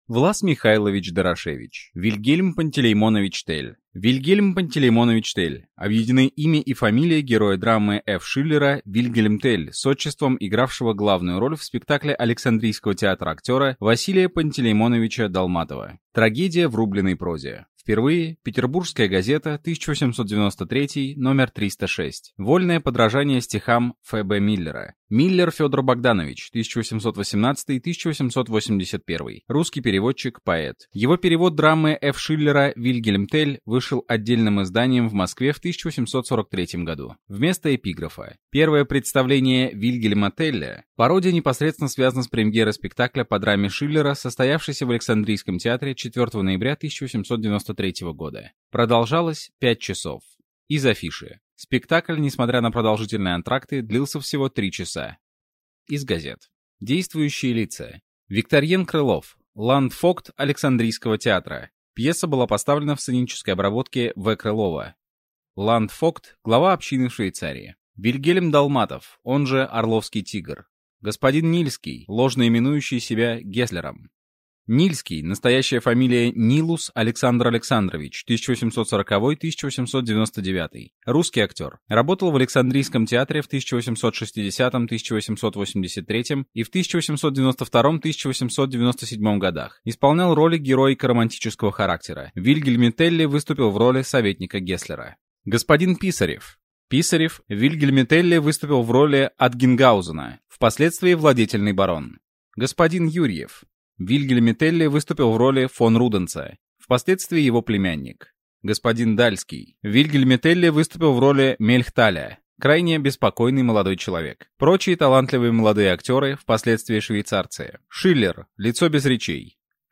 Аудиокнига Вильгельм Пантелеймонович Телль | Библиотека аудиокниг